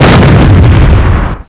explode.wav